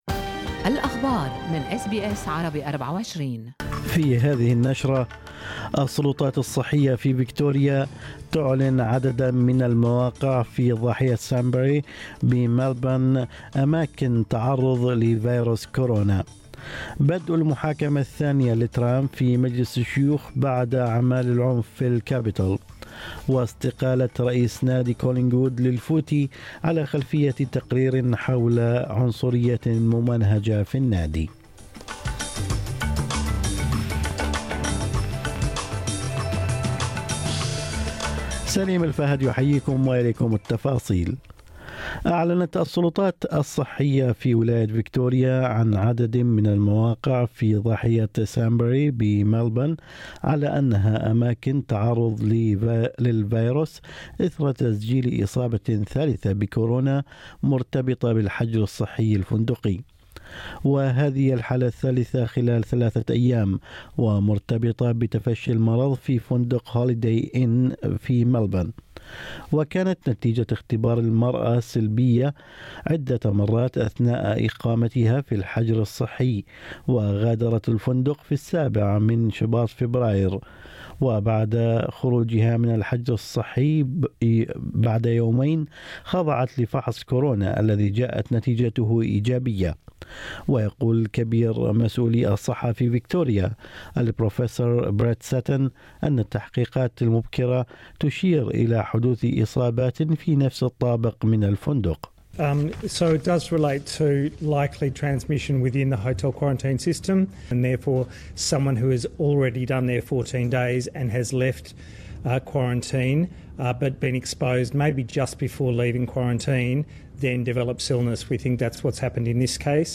نشرة أخبار الصباح 10/2/2021